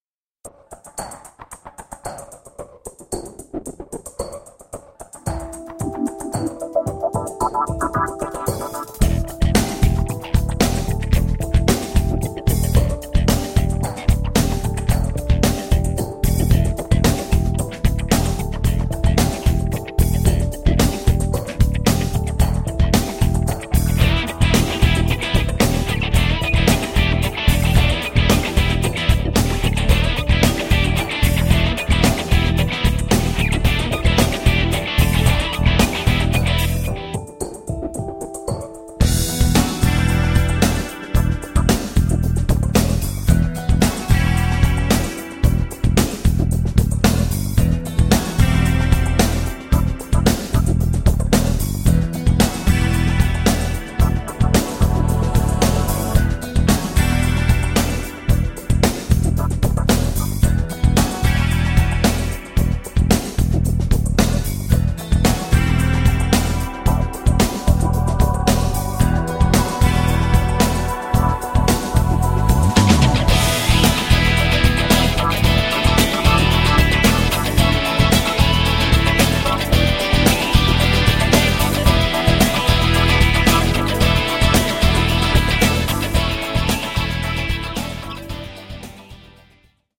Das Playback-Album zur gleichnamigen Produktion.
Playback ohne Backings 2,99 €